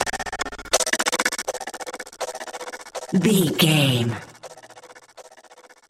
Short musical SFX for videos and games.,
Sound Effects
Epic / Action
Fast paced
In-crescendo
Ionian/Major
aggressive
energetic
funky